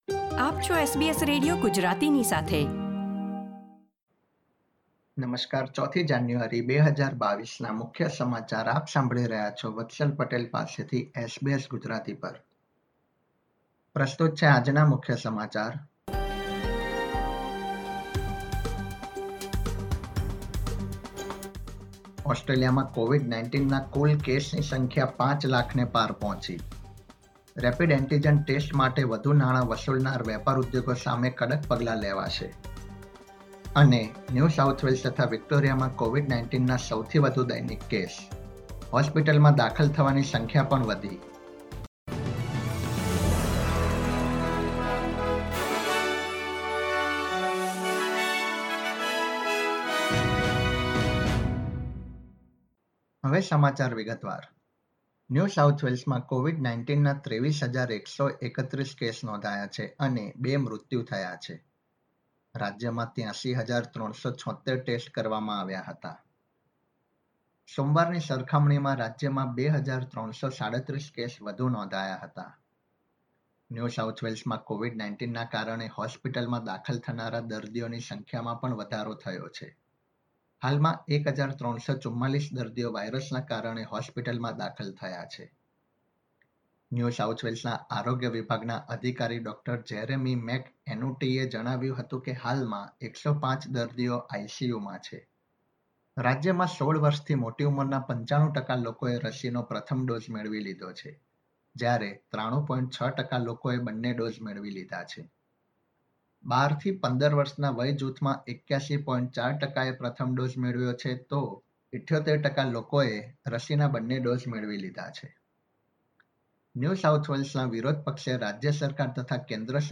SBS Gujarati News Bulletin 4 January 2022